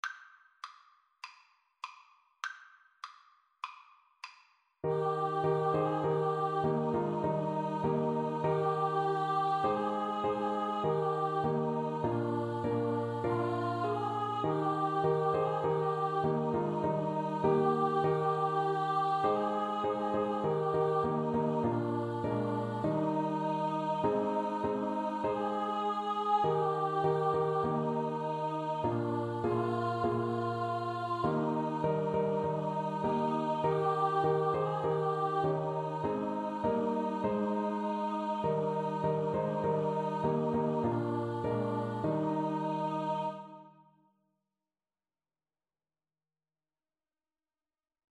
Free Sheet music for Choir (SATB)
4/4 (View more 4/4 Music)
Classical (View more Classical Choir Music)